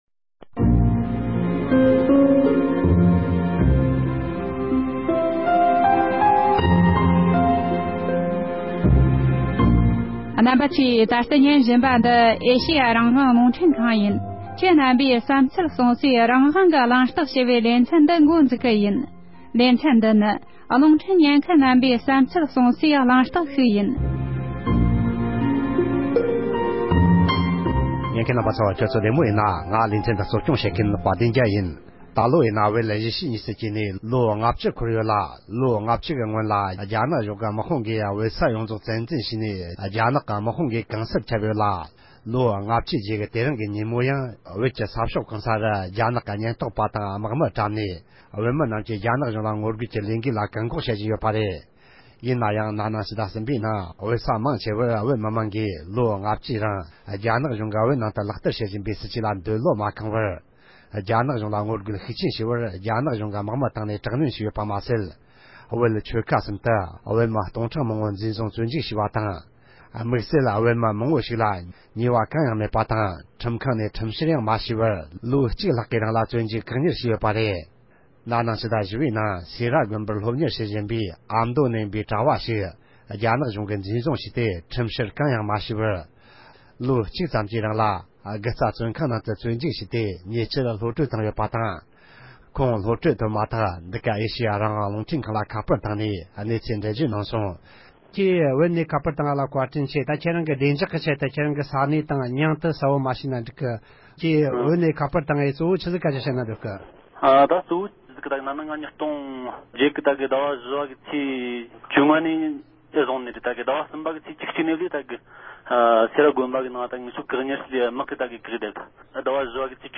རྒུ་རྩ་བཙོན་ཁང་ནས་ཉེ་ཆར་གློང་བཀྲོལ་བཏང་ཡོད་པའི་མི་སྣ་ཞིག་གིས་བོད་ཨ་མདོ་ནས་ཁ་པར་ཐོག་བཙོན་ཁང་སྐོར་འགྲེལ་བརྗོད་གནང་བ།